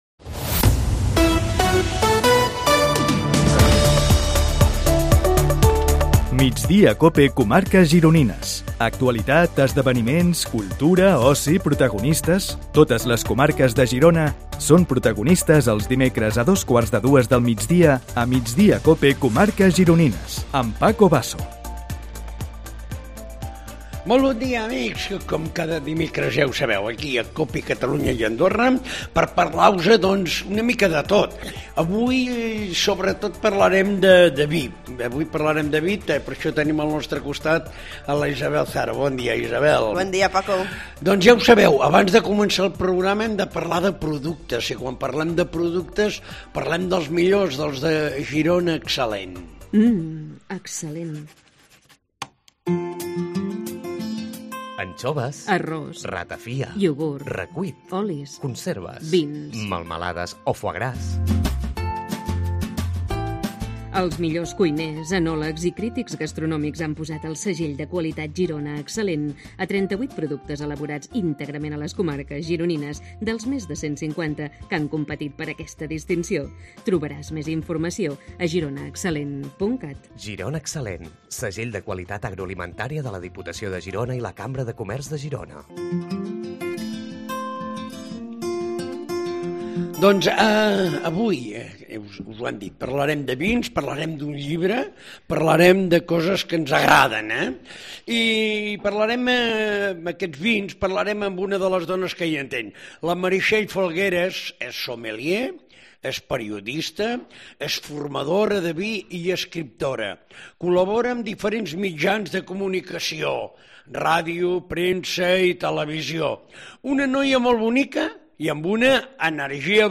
AUDIO: Entrevistem els protagonistes de l'actualitat a les comarques gironines
El nostre és un programa de ràdio que compte amb els millors ingredients.